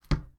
Footsteps
step2.wav